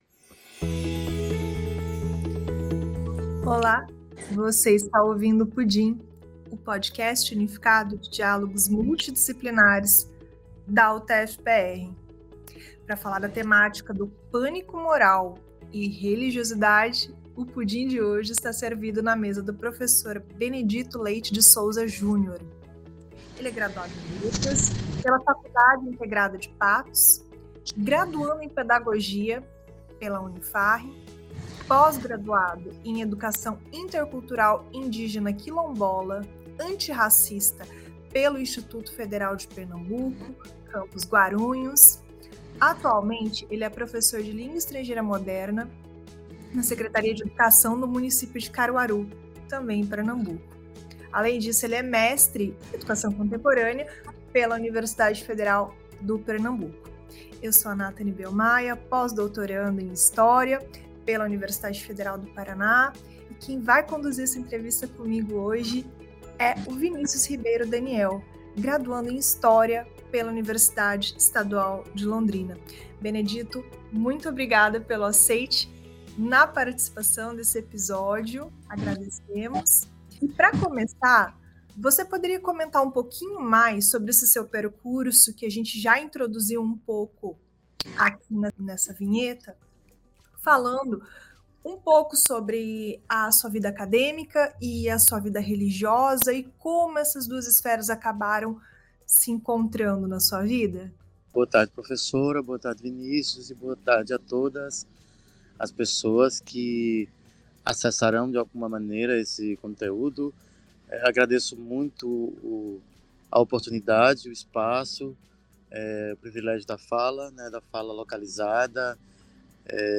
Exploramos com ele conceitos fundamentais para a educação contemporânea, como o Pânico Moral, os debates em torno da chamada “Ideologia de Gênero” e suas implicações diretas nas práticas docentes. Uma conversa sobre fé, ciênc